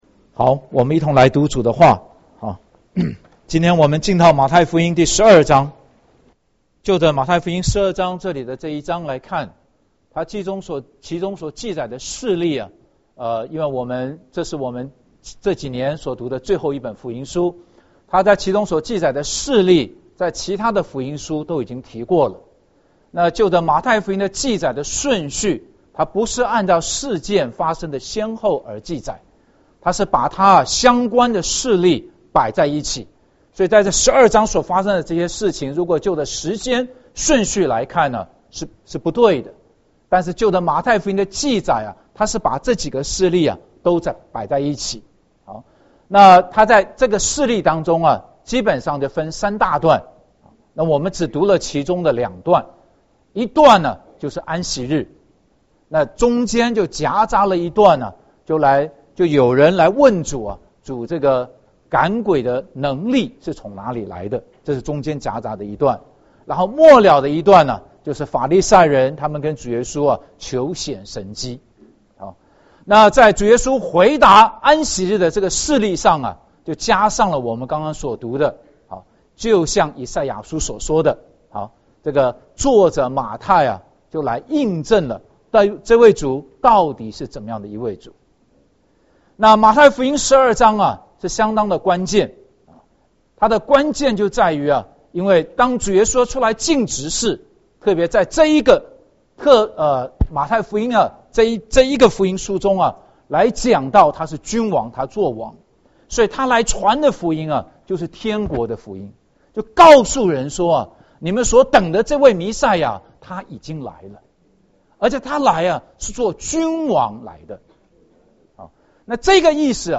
2012.9.9 主日信息